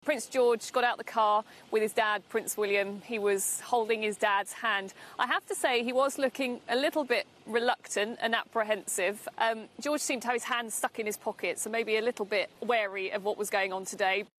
reports from Kensington Palace